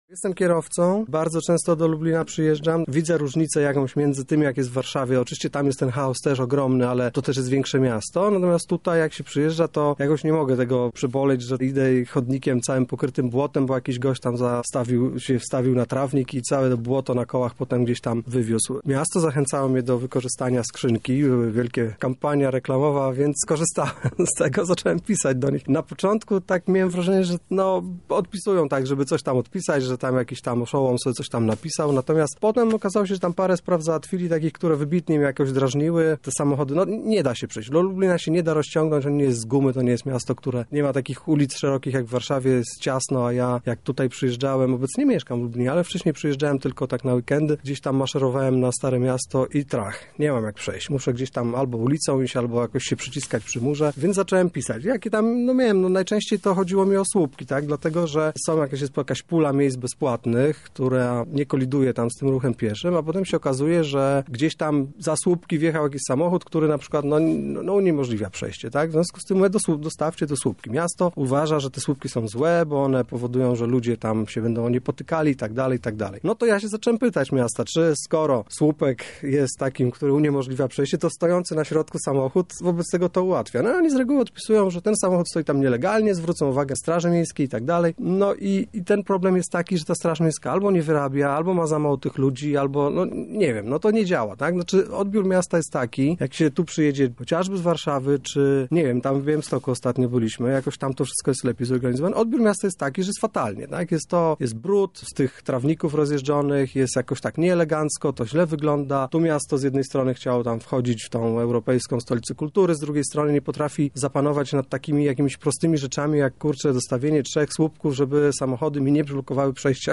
Nasz rozmówca zdecydował się zachować anonimowość